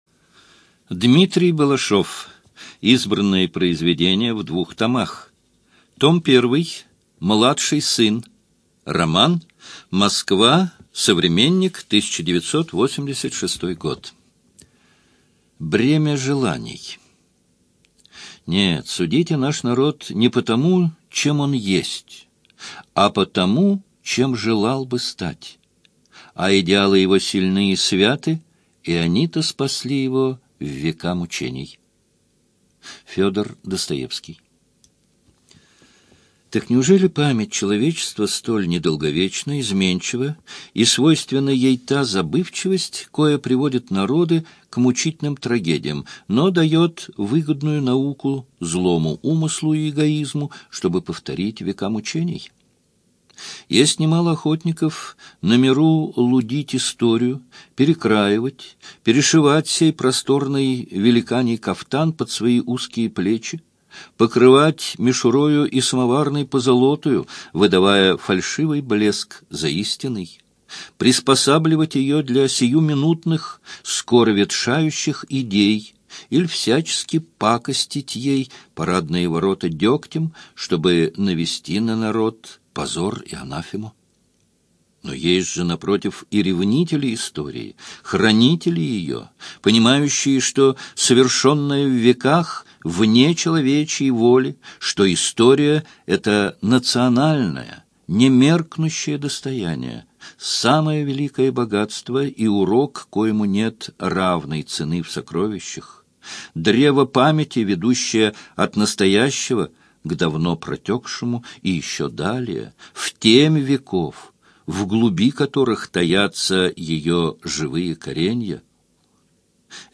ЖанрИсторическая проза
Студия звукозаписиЛогосвос